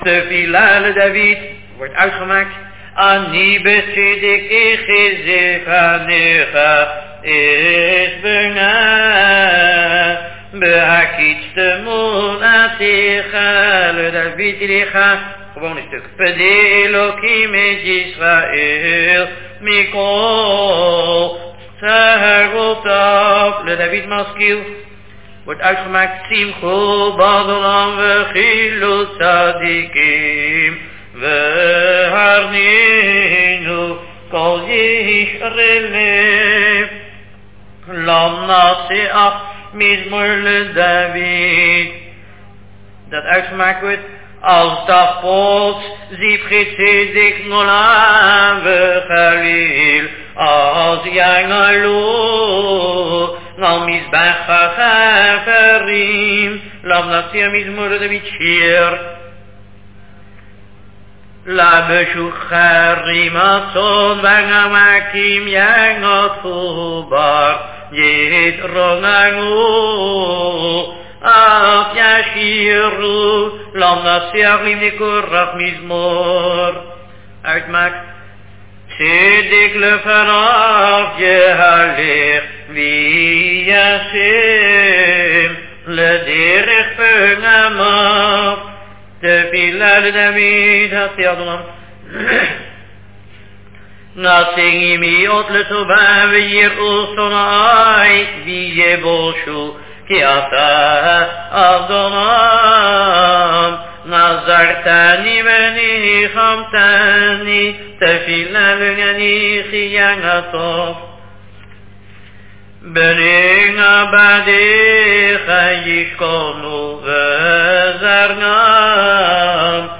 In general, the chazzan recites aloud only the first sentence of each mizmor. The last sentence is sung by the community, and then the by the chazzan.
Most of the recordings are taken from zemirot Shabbat.